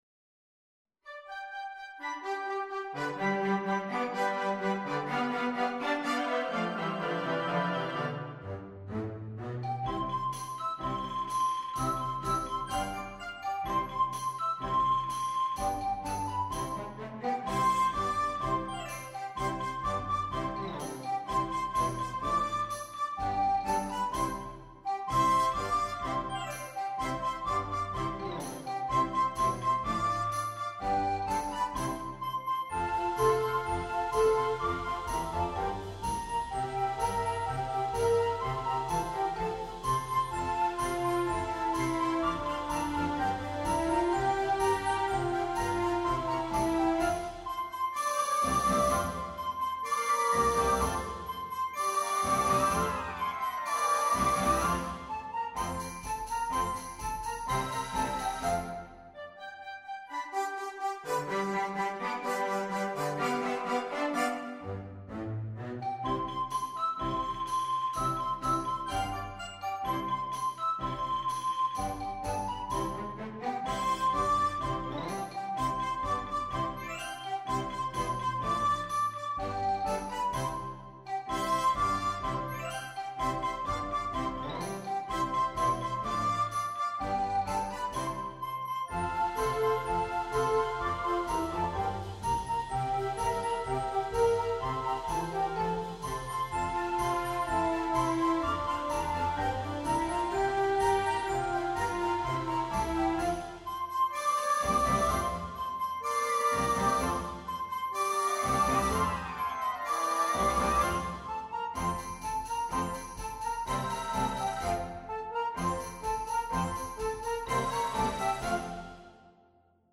EL PATIO DE MI CASA, canción popular, para flauta de pico y banda (2') - Fernando Bonete Piqueras
Aquí podrás escuchar, a modo de demostración, algunos fragmentos de la transcripción o instrumentación
Demo-audio-EL-PATIO-DE-MI-CASA-con-flauta.mp3